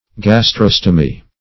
Gastrostomy \Gas*tros"to*my\, n. [Gastro- + Gr. ? mouth.]